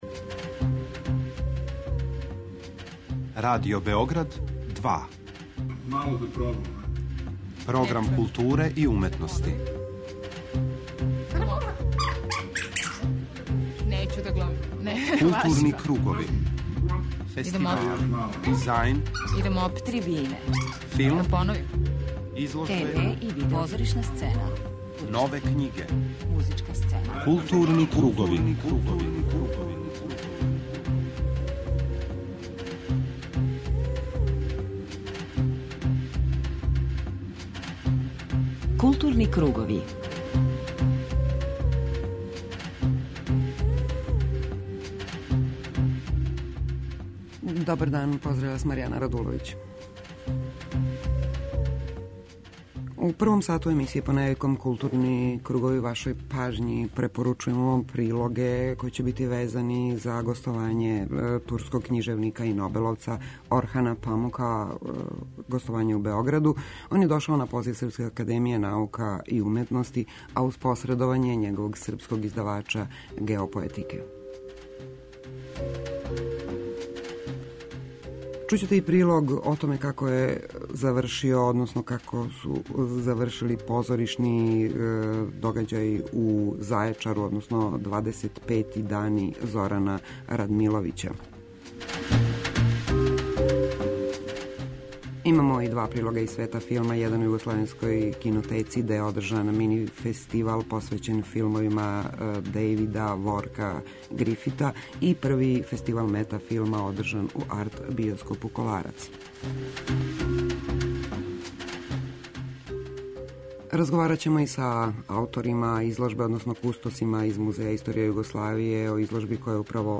преузми : 39.69 MB Културни кругови Autor: Група аутора Централна културно-уметничка емисија Радио Београда 2.